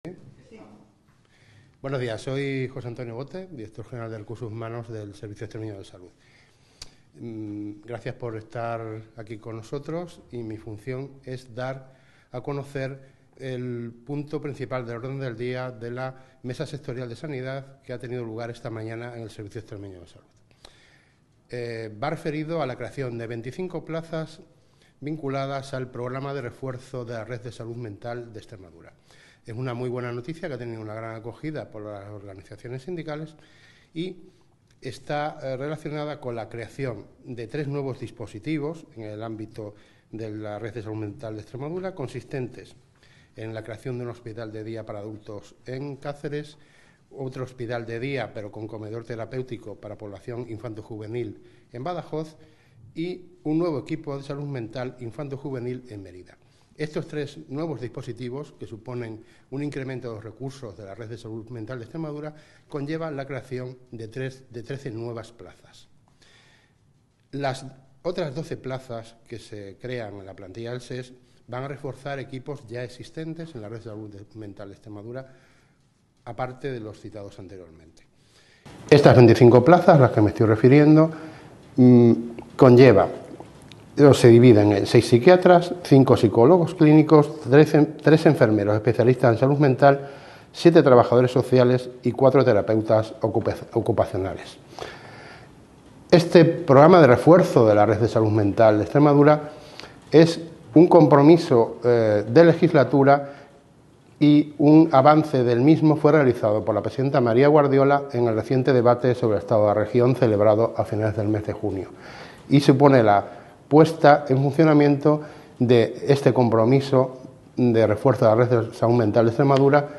Audio del director general de Recursos Humanos del Servicio Extreme�o de Salud (SES), Jos� Antonio Bote .